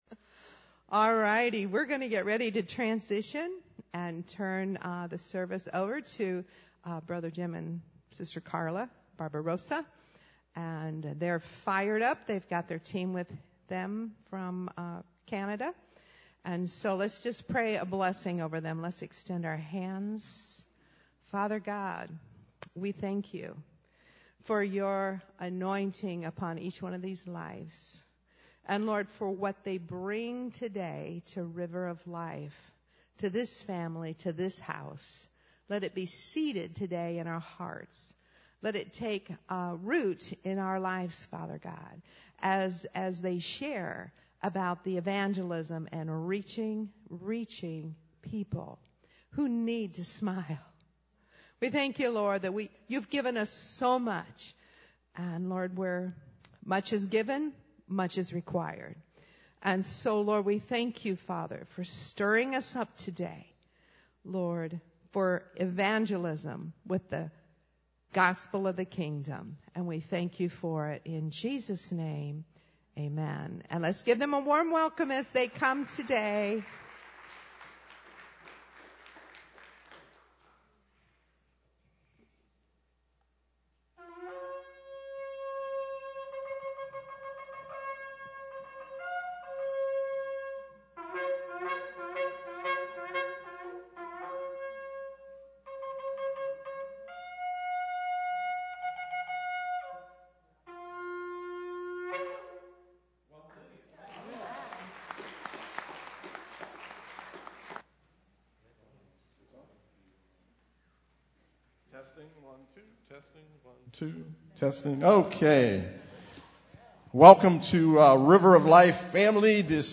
Sermons Archive - Page 32 of 47 - River of Life Community Church